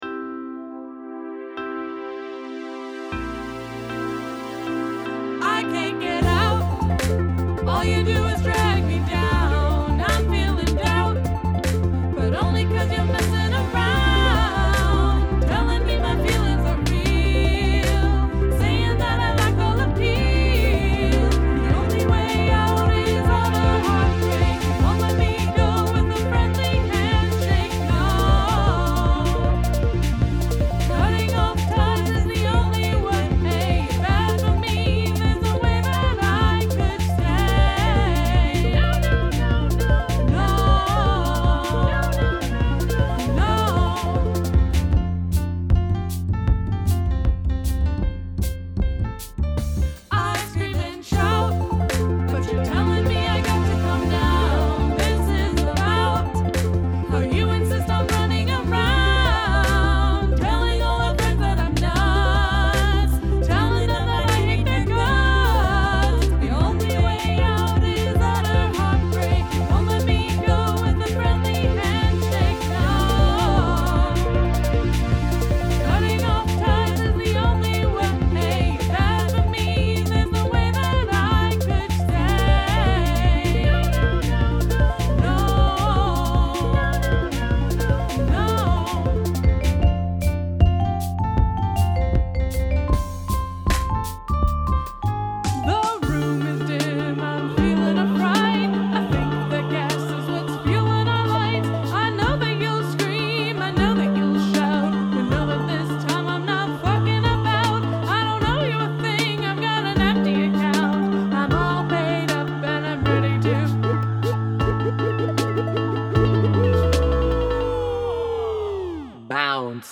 Downward Modulation
The switch-up for the ending is wild!